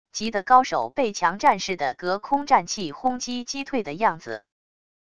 级的高手被强战士的隔空战气轰击击退的样子wav音频